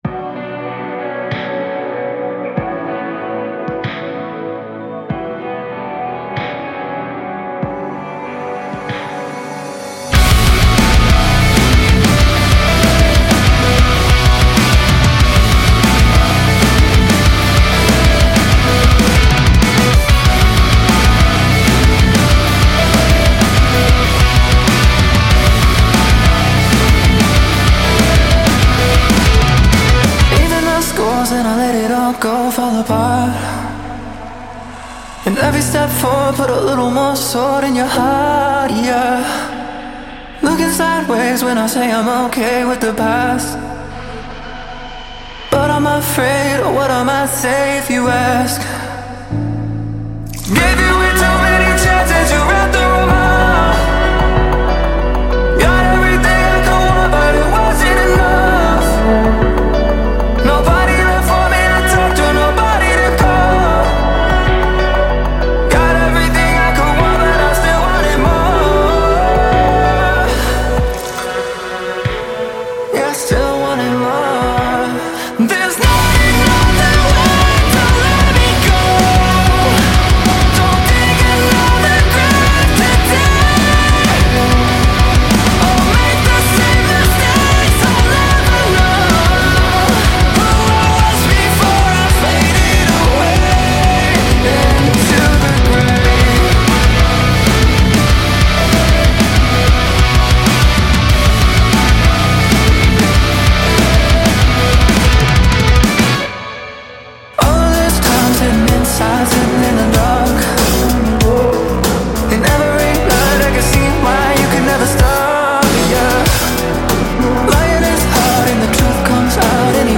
متال Metal